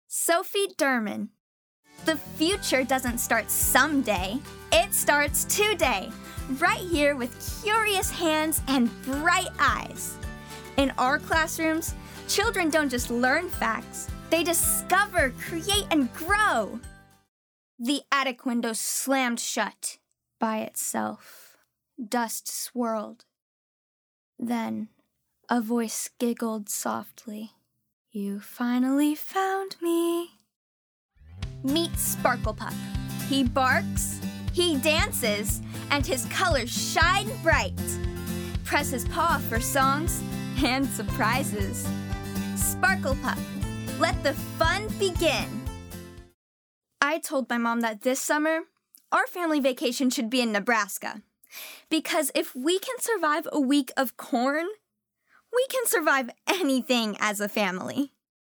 Gender: Female
VO DEMO